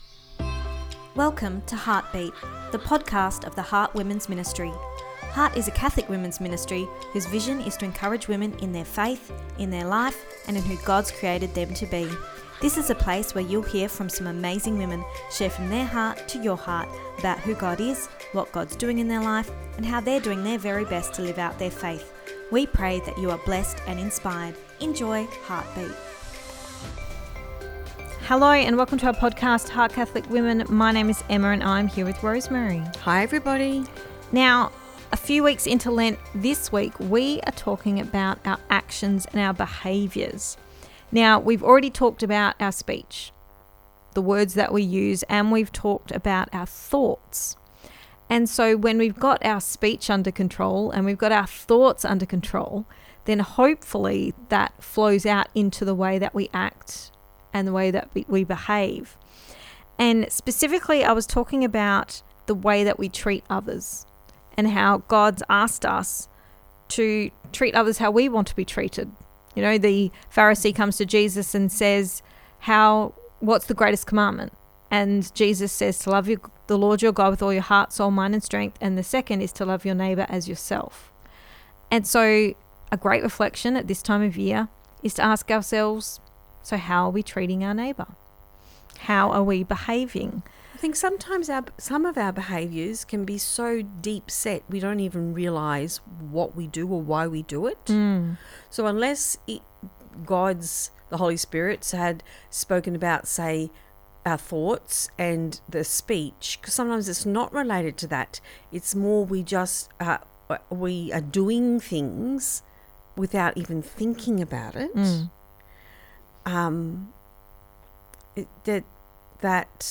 Ep288 Pt2 (Our Chat) – Lent: Actions Speak Louder than Words